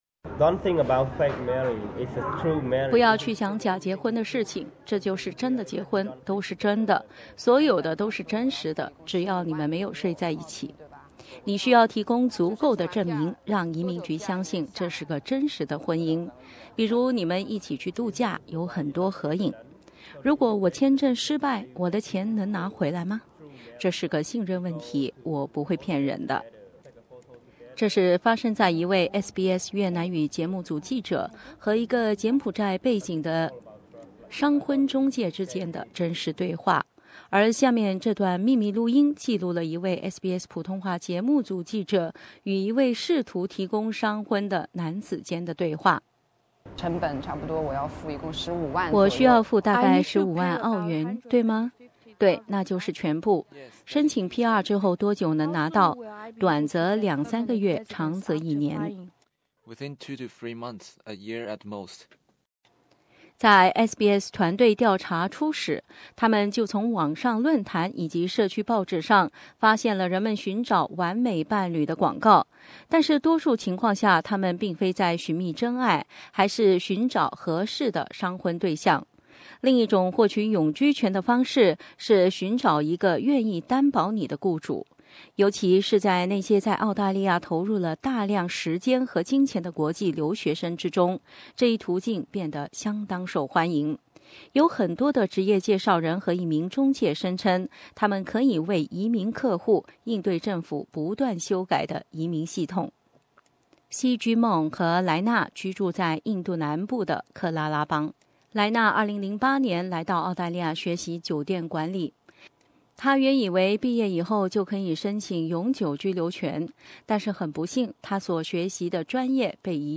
而下面这段秘密录音记录了一位 SBS 普通话节目组记者与一位试图提供商婚的男子间的对话。